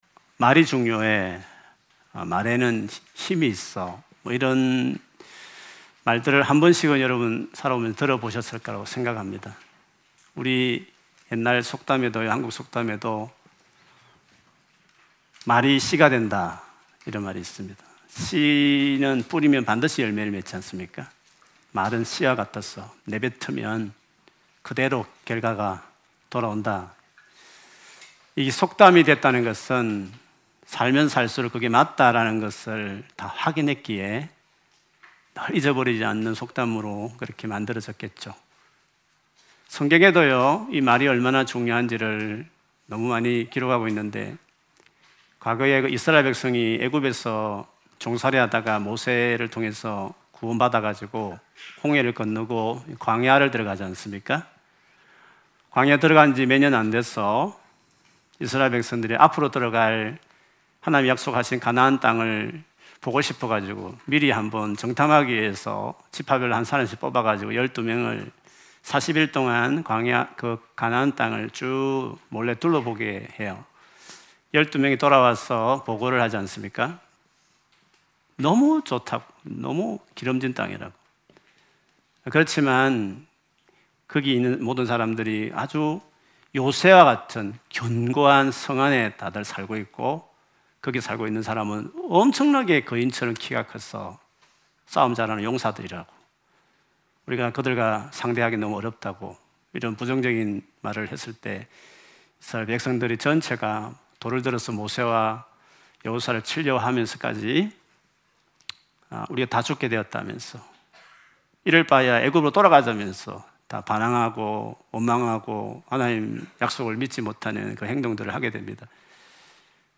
2025년 11월 16일 주일예배 설교